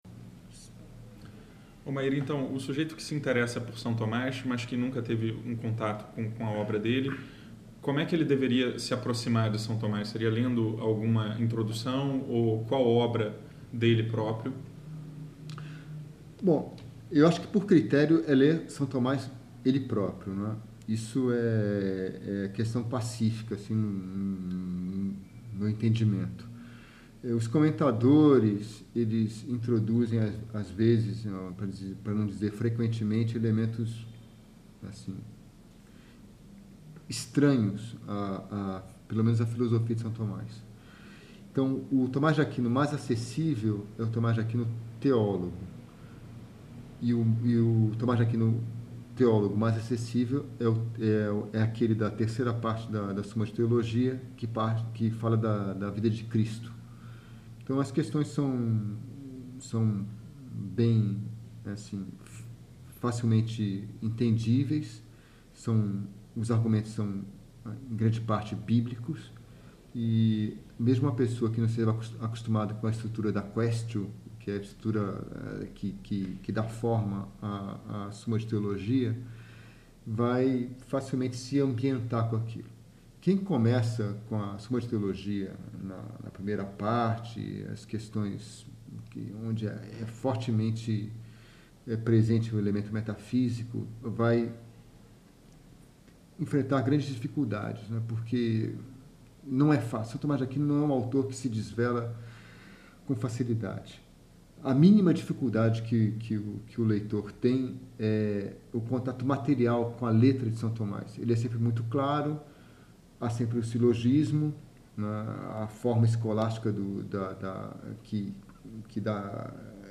uma entrevista